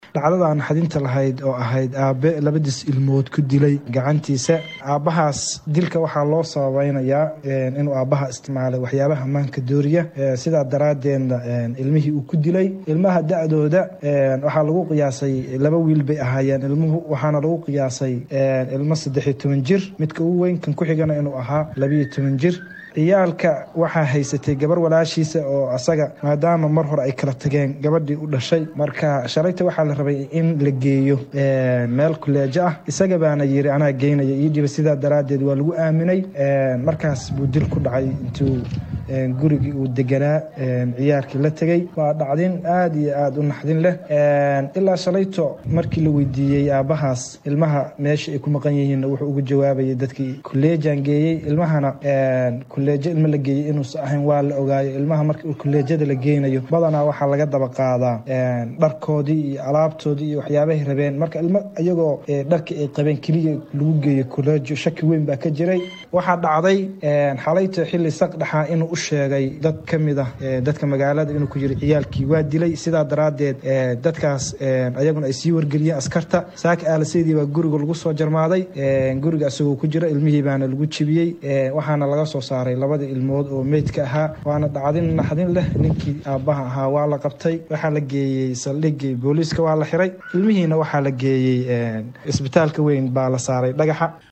Dadka deegaanka oo warbahinta la hadlay ayaa ka warbixiyay sida ay wax uu dhaceen.